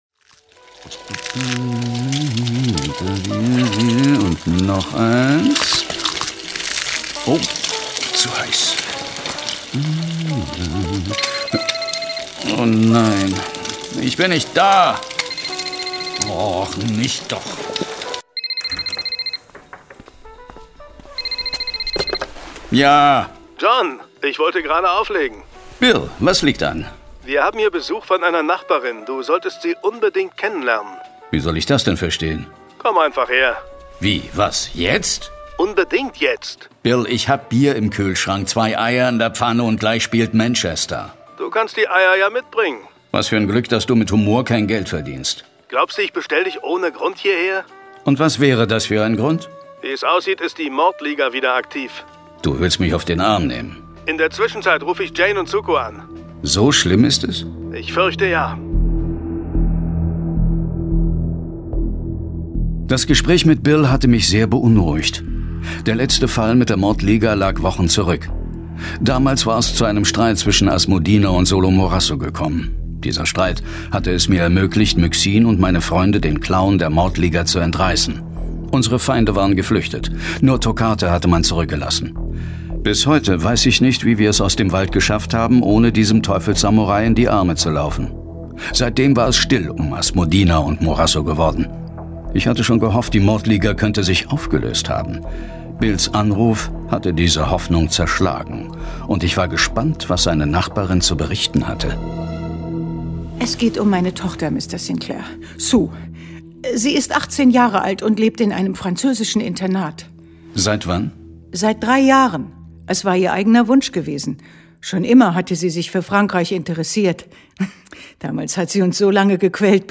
John Sinclair - Folge 47 Die Werwolf-Sippe - Teil 1 von 2. Hörspiel.